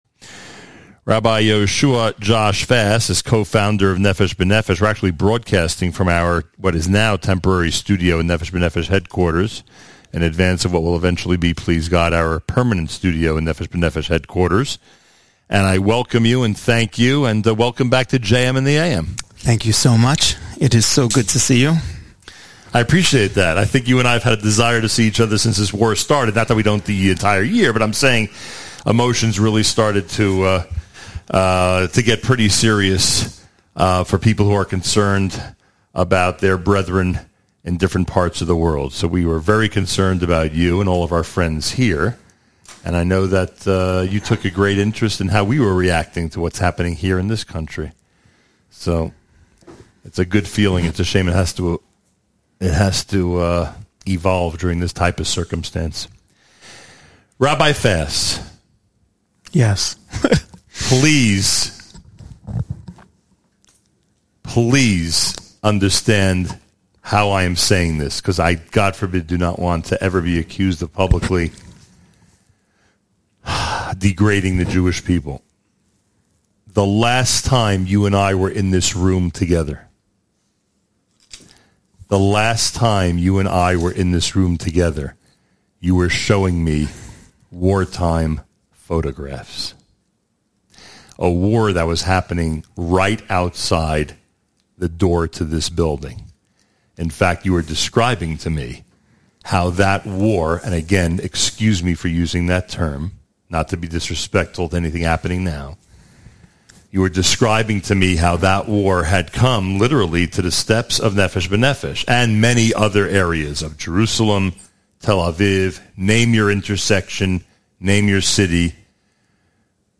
presented the first of two Israel-based broadcasts live from the Nefesh B’Nefesh headquarters in Jerusalem this morning.